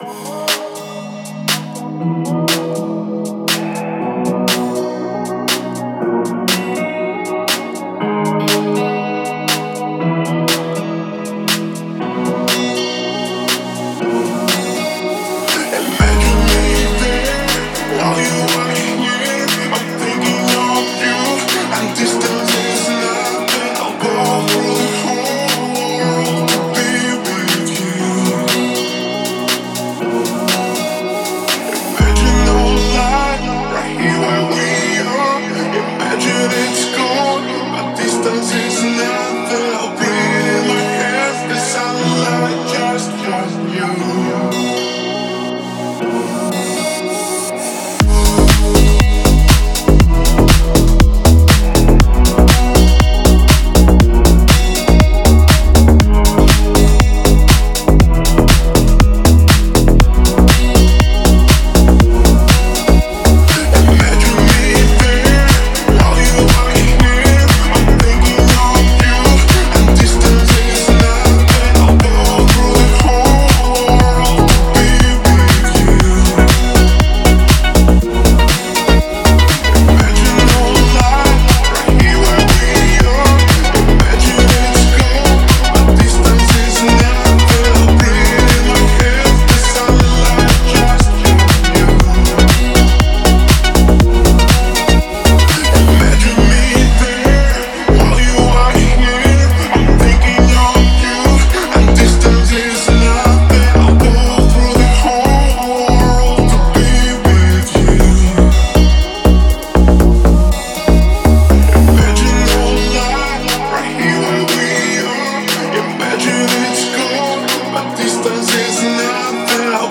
который сочетает в себе элементы поп и электронной музыки.